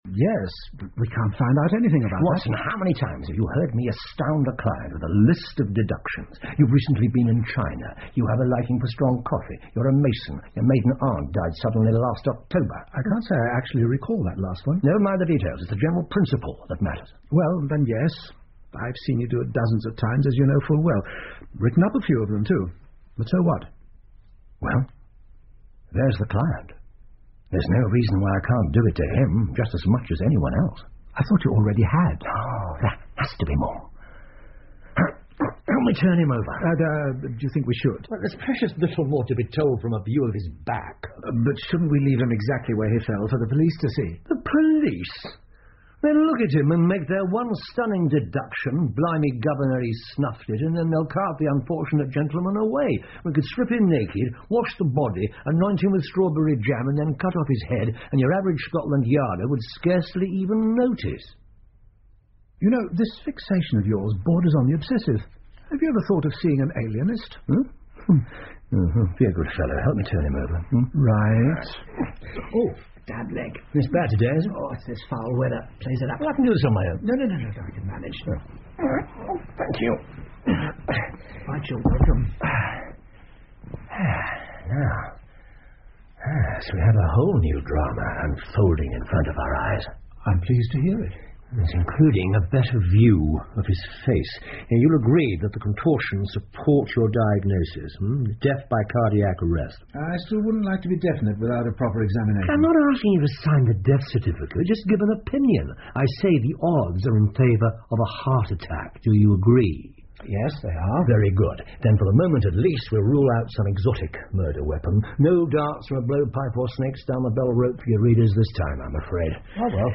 福尔摩斯广播剧 The Abergavenny Murder 5 听力文件下载—在线英语听力室